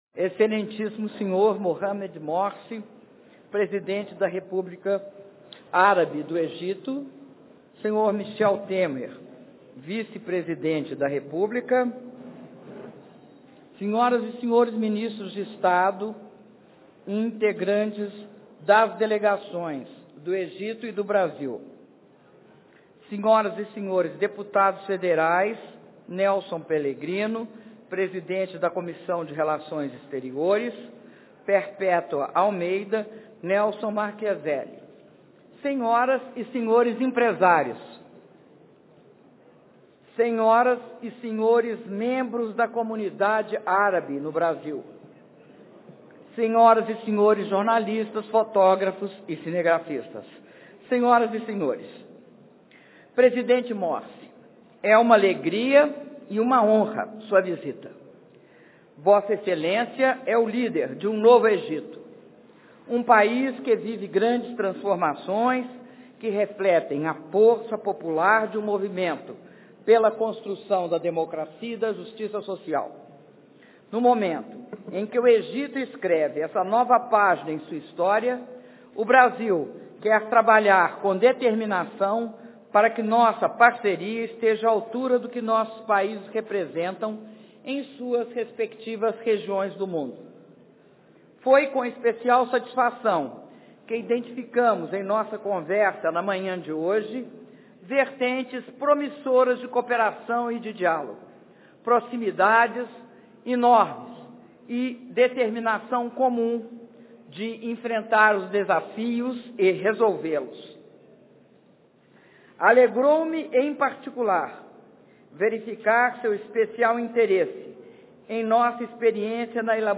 Brinde da Presidenta da República, Dilma Rousseff, durante almoço em homenagem ao Presidente do Egito, Mohamed Morsi
Palácio Itamaraty, 08 de maio de 2013